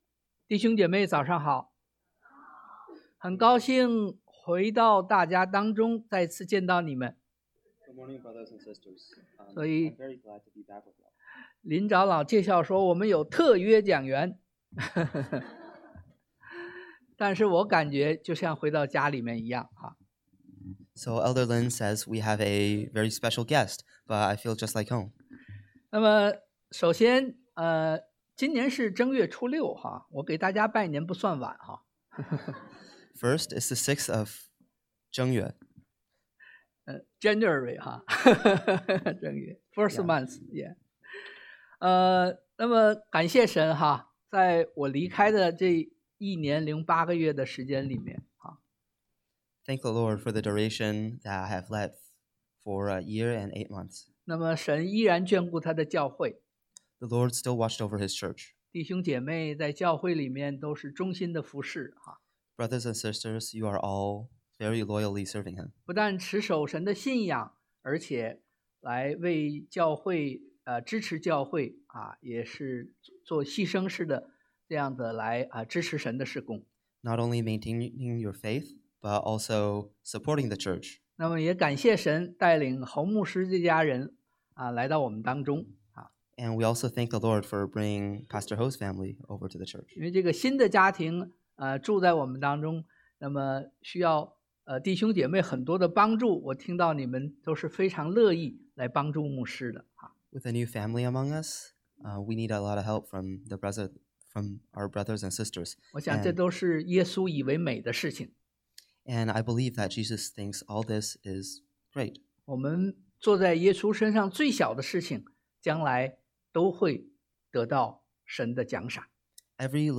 Service Type: Sunday AM 六種神的聲音 Six kinds of God’s voice 三個相爭國度 Three competing kingdoms 安靜聆聽 Be still and attentive!